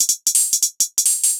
UHH_ElectroHatB_170-01.wav